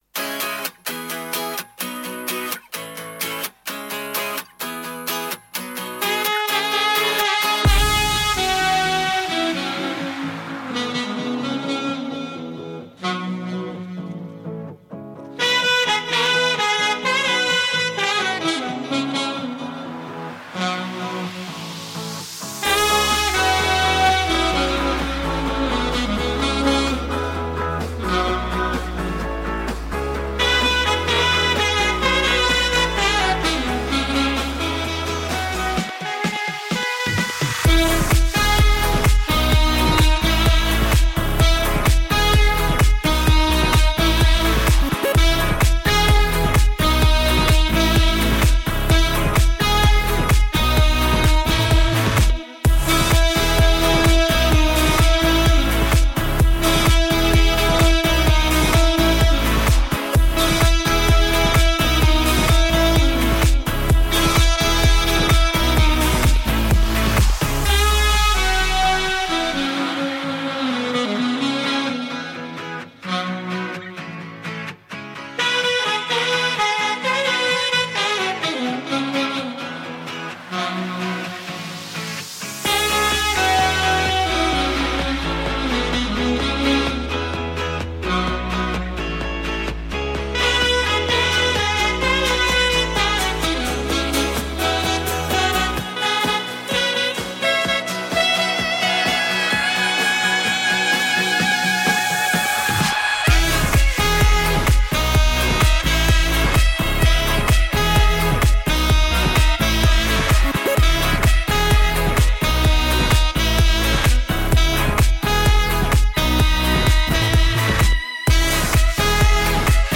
radiomarelamaddalena / STRUMENTALE / SAX /